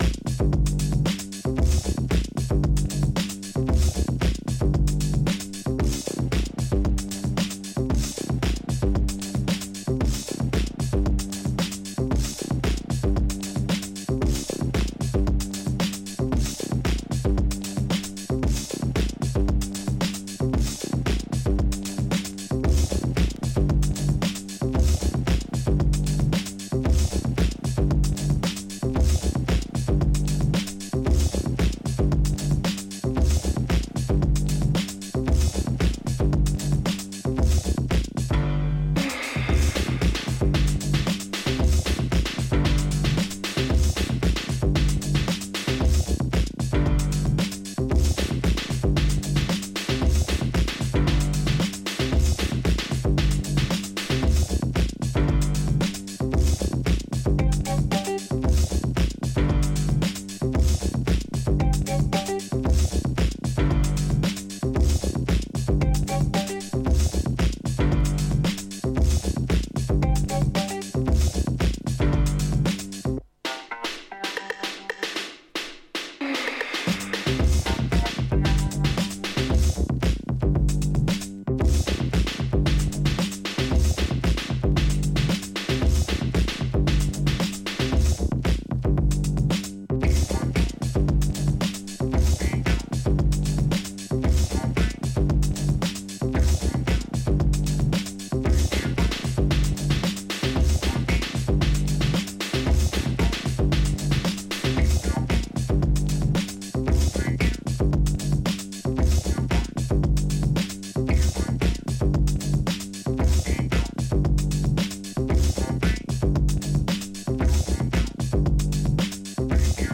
場面に応じて、よりBPMを抑えたB面の方も使えそうですね。
Instrumental